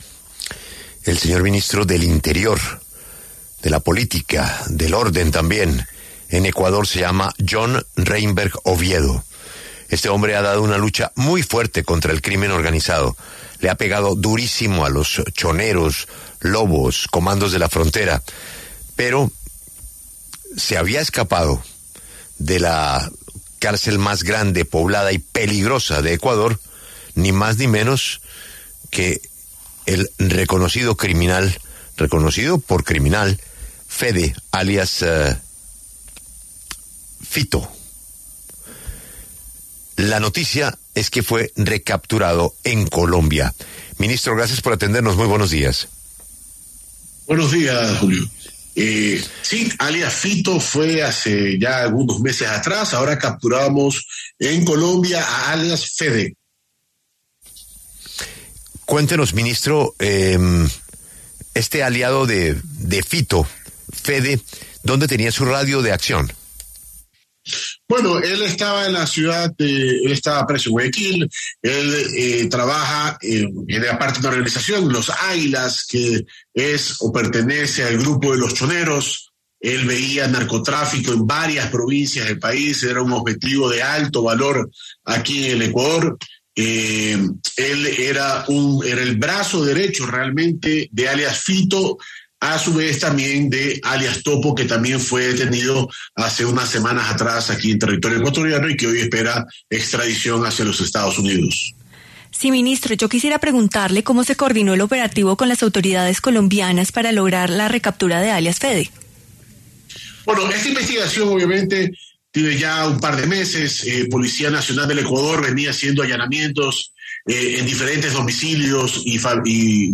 John Reimberg Oviedo, ministro del Interior de Ecuador, conversó con Julio Sánchez Cristo para La W sobre las acciones adoptadas en su país contra el crimen organizado y las bandas delictivas como ‘Los Choneros’, ‘Los Lobos’ y ‘Comandos de la Frontera’.